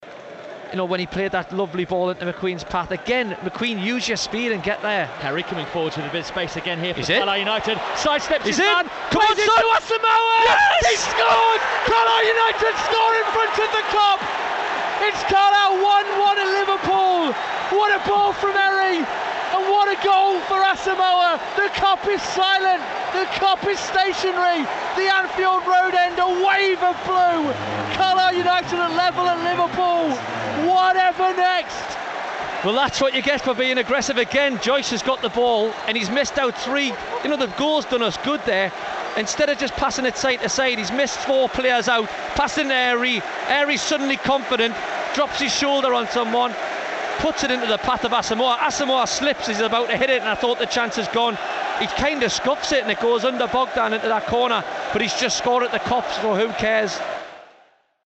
AUDIO: Hear the BBC Radio Cumbria Sport commentary on Carlisle United's equaliser at Liverpool last night